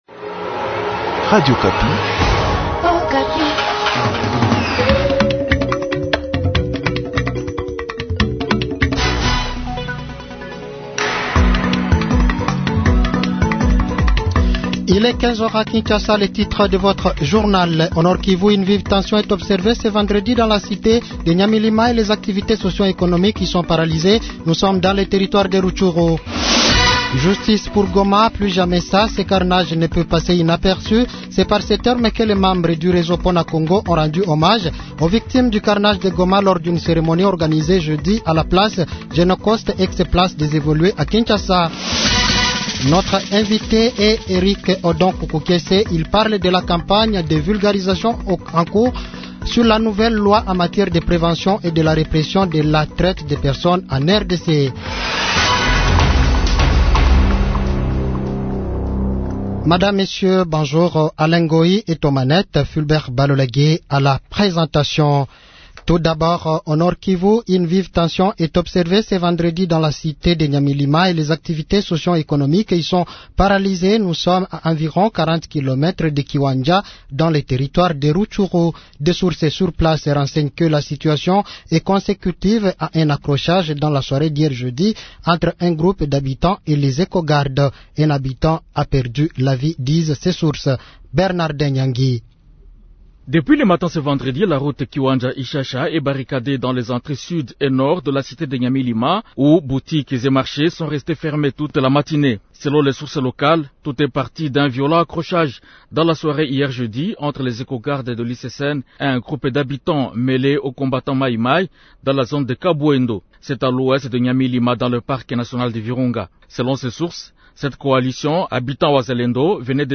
JOURNAL PARLE